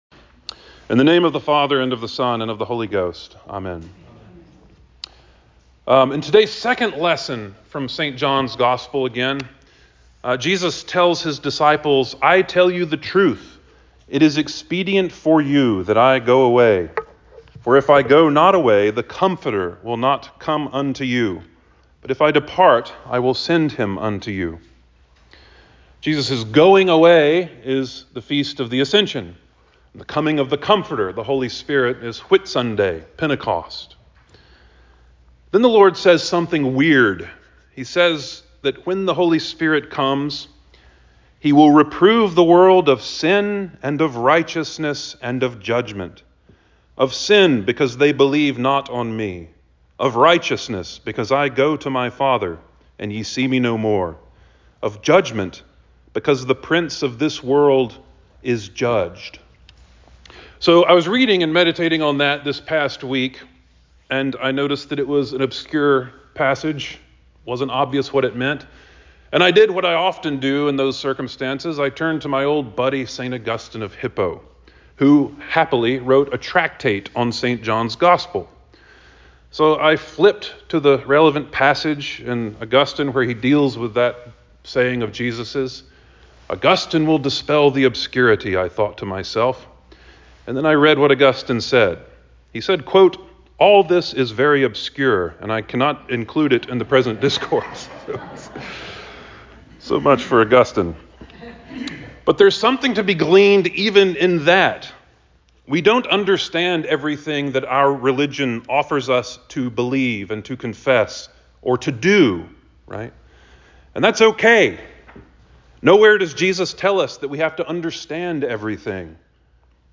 Sermon for The Fourth Sunday After Easter 04.28.24